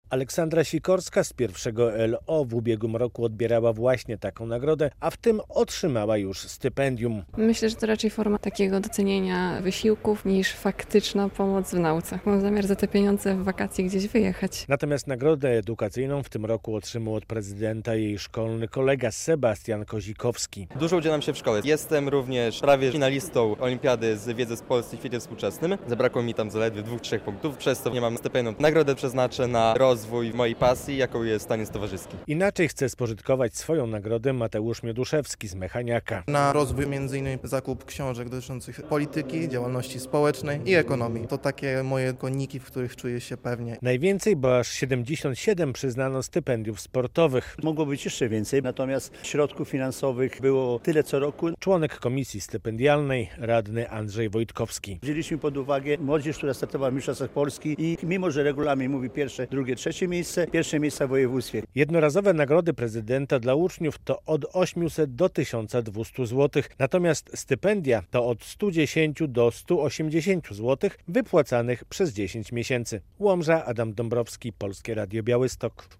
Mogło być ich jeszcze więcej, bo zgłoszeń mieliśmy bardzo dużo, ale ze względu na ogólną sumę, musieliśmy to okroić. I tak np. w tym roku nie otrzymali już stypendiów zdobywcy drugich i trzecich miejsc w zawodach wojewódzkich, tylko ich zwycięzcy - tłumaczy członek kapituły, radny Andrzej Wojtkowski.